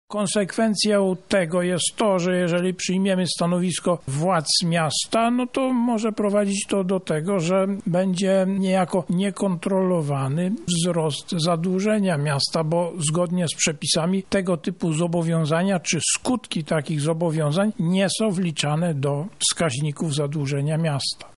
Taka decyzja może mieć niekorzystny wpływ na finanse publiczne – zaznacza Prezes Regionalnej Izby Obrachunkowej w Lublinie, Jacek Grządka